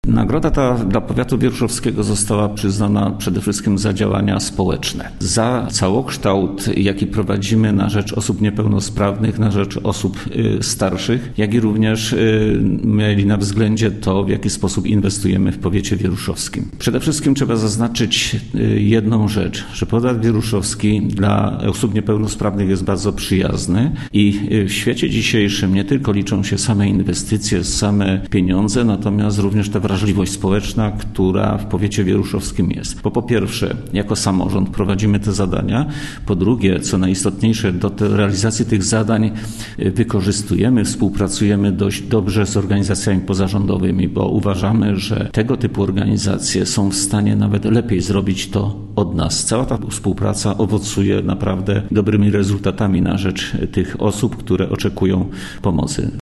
– mówił wicestarosta powiatu wieruszowskiego, Stefan Pietras.